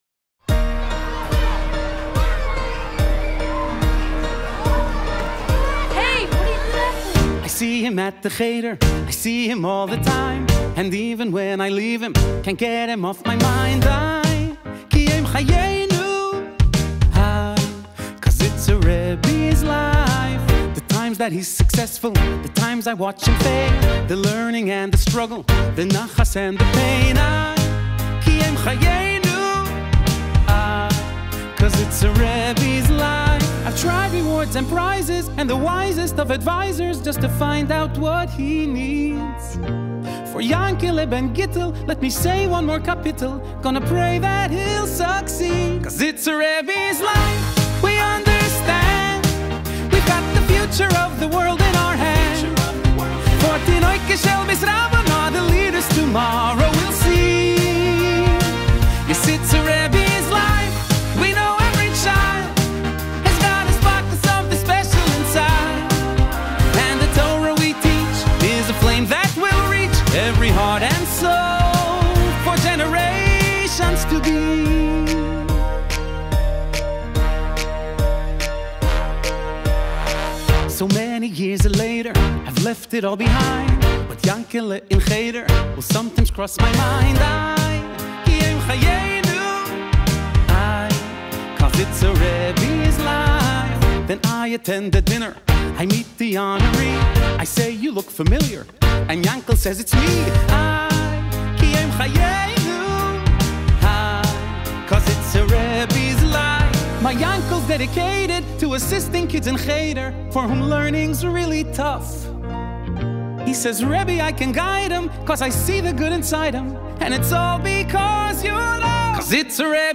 בגרסה ווקאלית מיוחדת לימי ספירת העומר